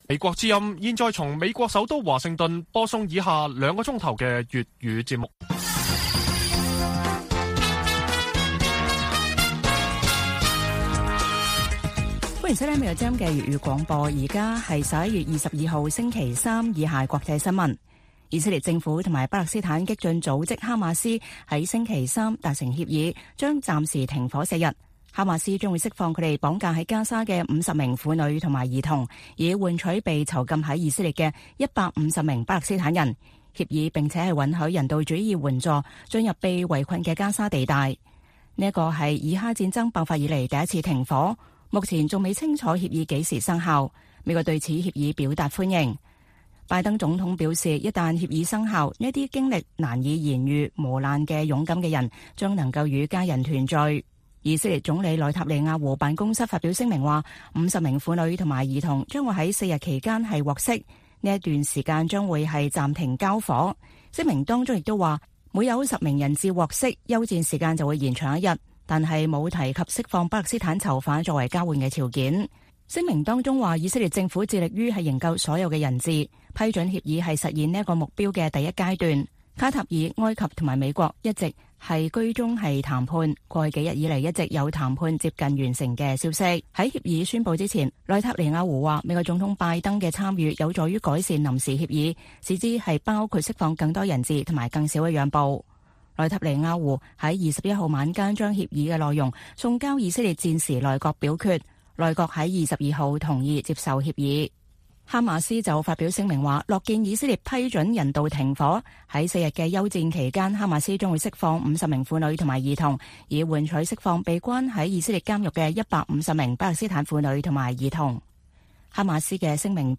粵語新聞 晚上9-10點: 以色列、哈馬斯達成協議，哈馬斯釋放50名人質並停火四日